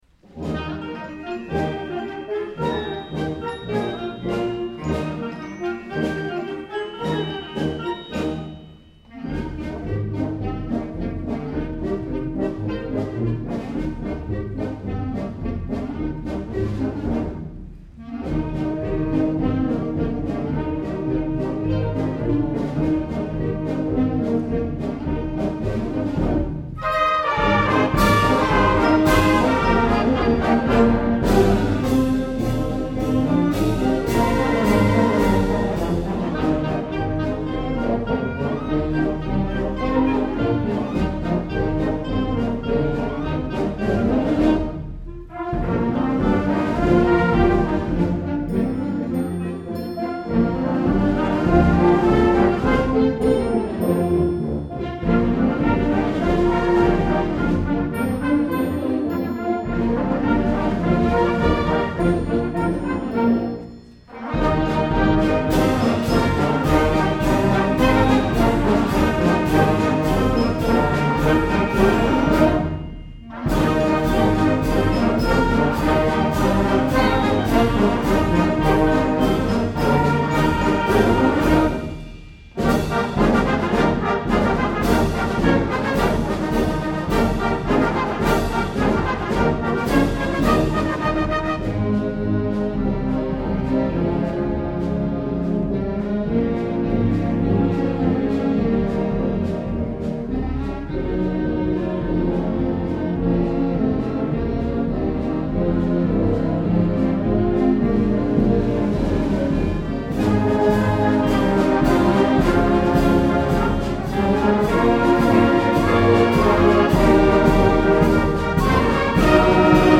2008 Summer Concert
June 22, 2008 - San Marcos High School